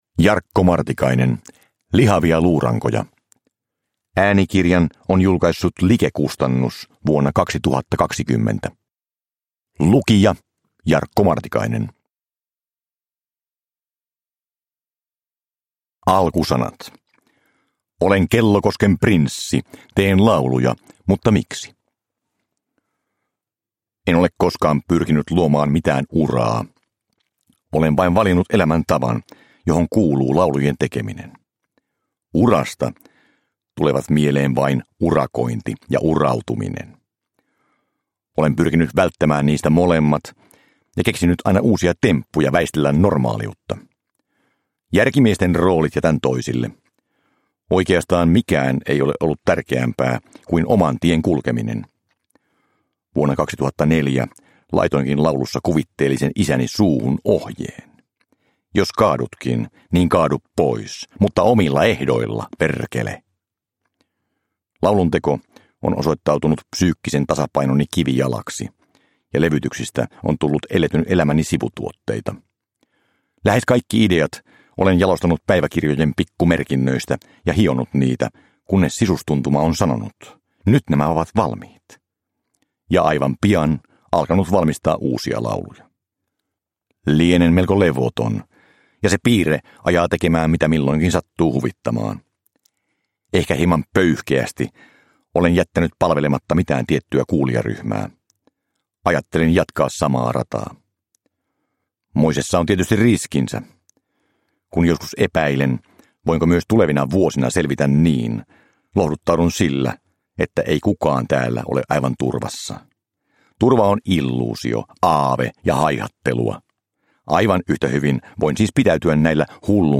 Lihavia luurankoja, laulujen sanat ja tarinat – Ljudbok – Laddas ner
Uppläsare: Jarkko Martikainen